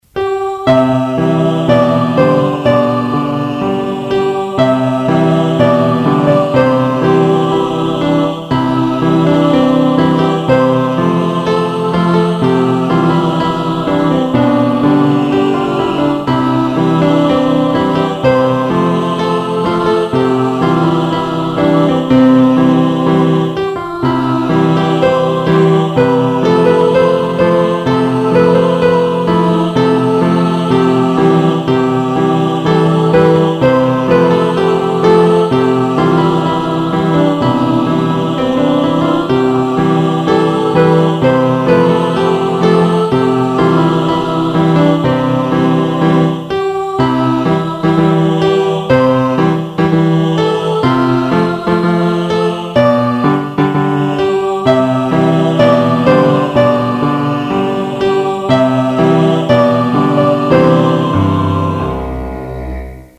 校歌雙聲道.mp3